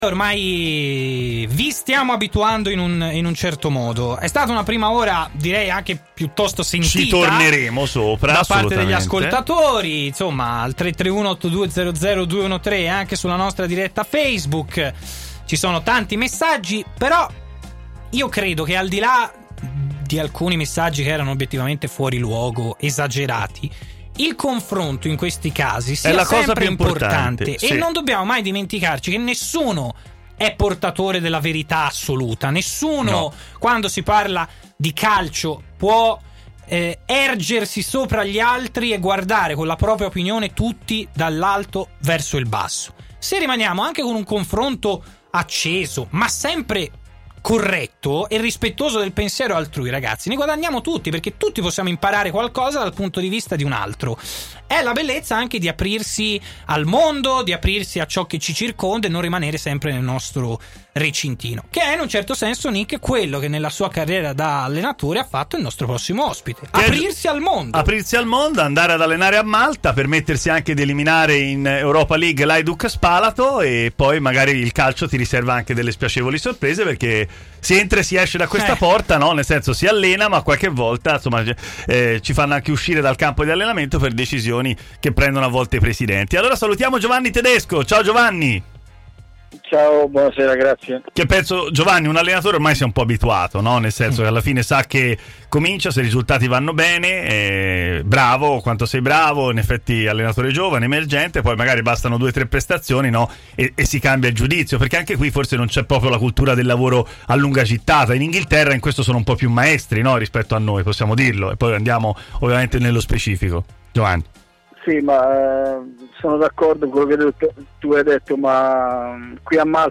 Giovanni Tedesco ai microfoni di “Stadio Aperto”, trasmissione di TMW Radio, parla dello scomparso presidente Gaucci, della sua carriera da calciatore e delle nuove sfide nelle vesti di allenatore.